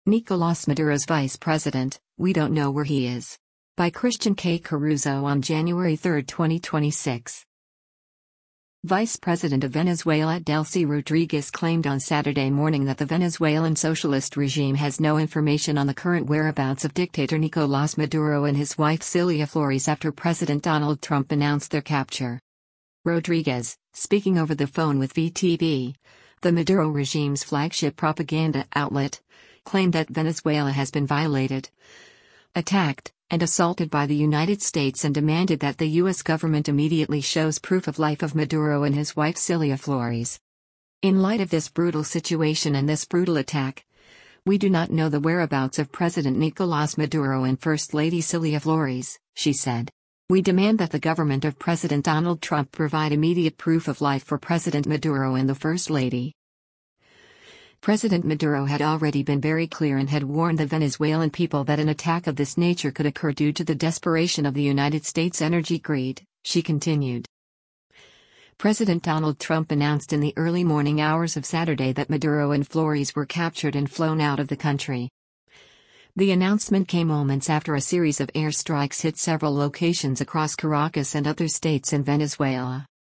Rodríguez, speaking over the phone with VTV, the Maduro regime’s flagship propaganda outlet, claimed that Venezuela has been “violated, attacked, and assaulted” by the United States and demanded that the U.S. government immediately shows “proof of life” of Maduro and his wife Cilia Flores.